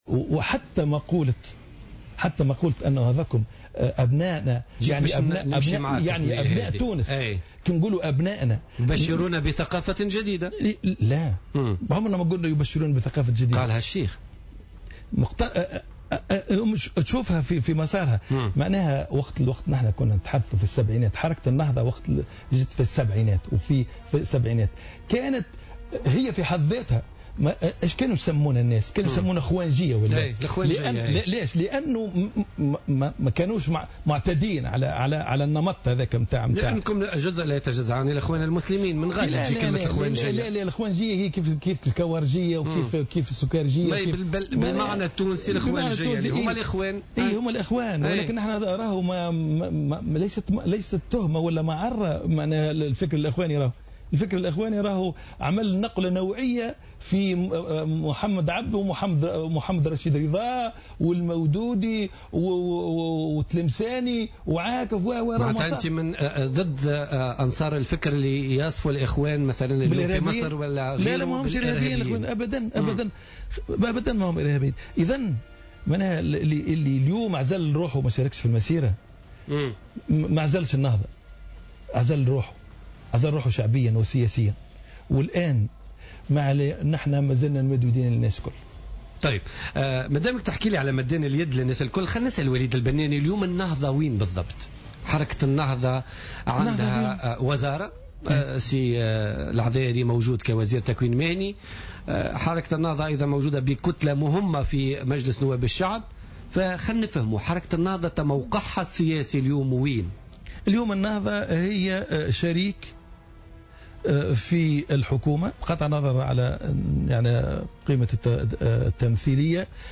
قال القيادي في حركة النهضة وليد البناني ضيف حصة بوليتيكا ليوم الاثنين 30 مارس، إن...